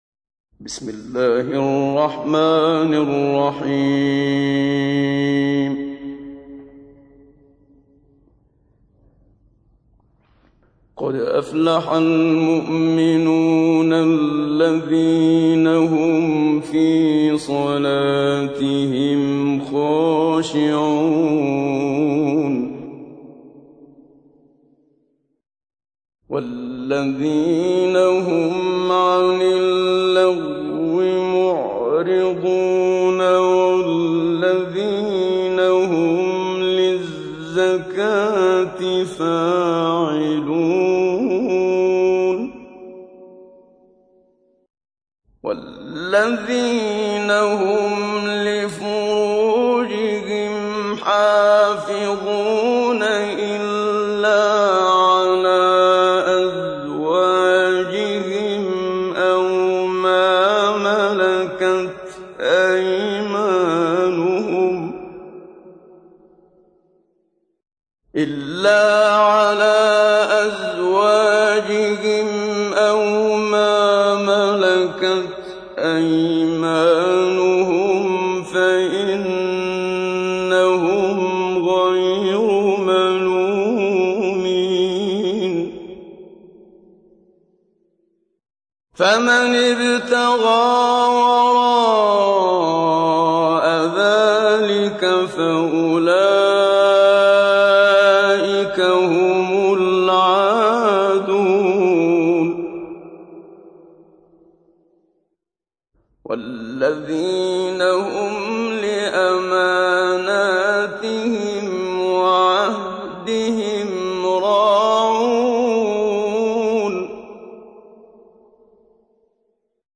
تحميل : 23. سورة المؤمنون / القارئ محمد صديق المنشاوي / القرآن الكريم / موقع يا حسين